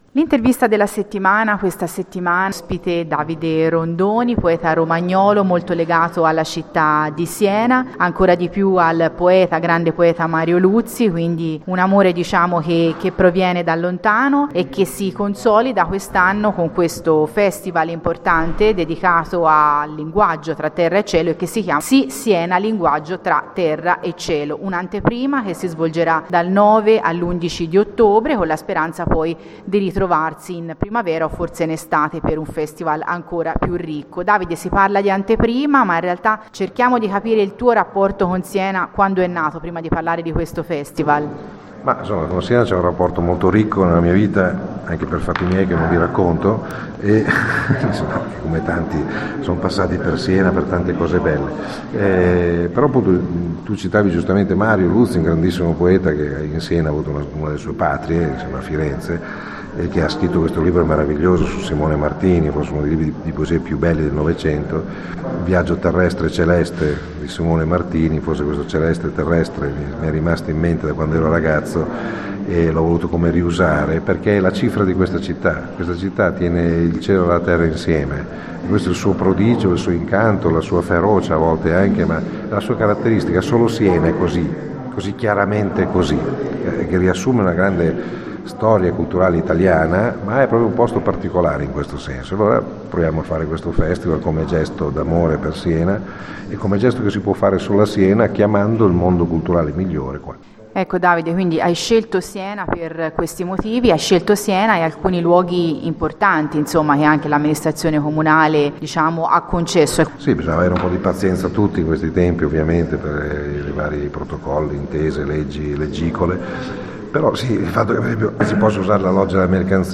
L’intervista della settimana – Ospite il poeta romagnolo Davide Rondoni